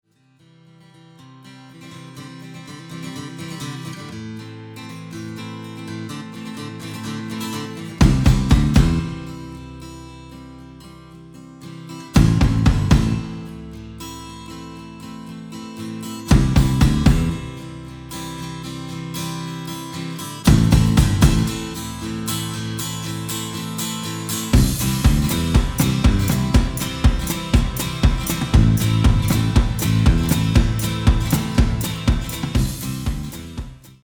Instumental